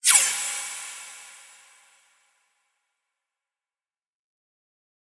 pause-retry-click.ogg